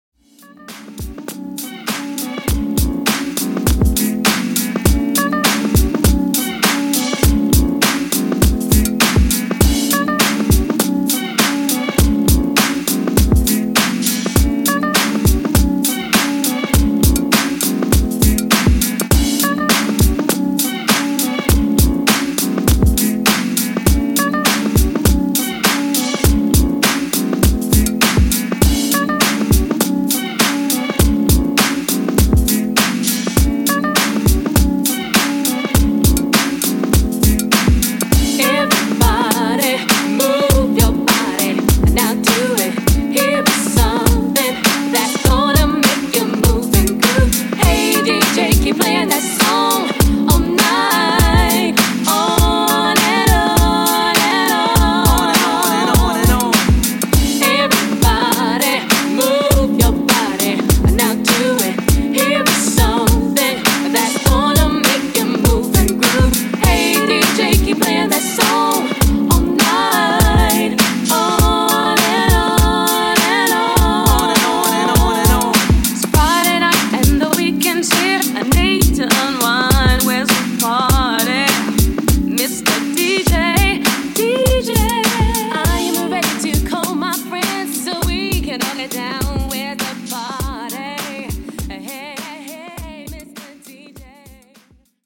90s R&B Redrum)Date Added